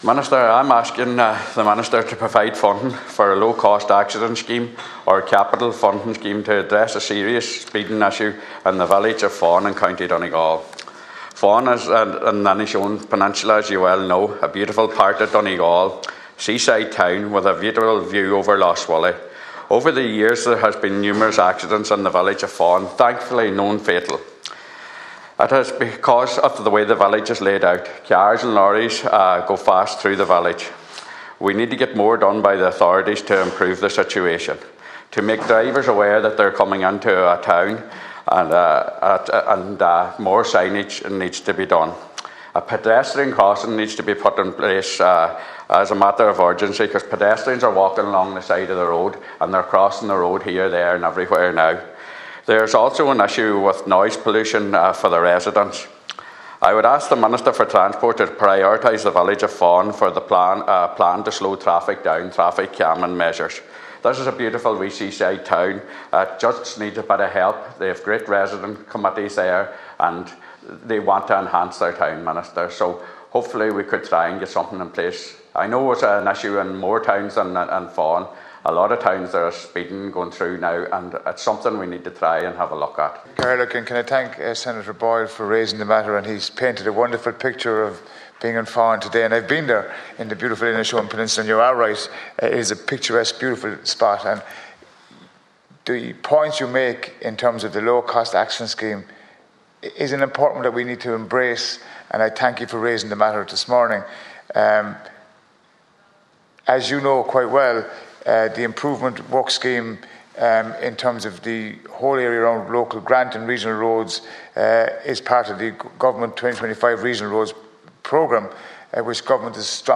The need for action to address speeding in Fahan has been raised in the Seanad.
Senator Boyle told Minister Jerry Buttimer that the close proximity to the border needs to be taken into consideration also: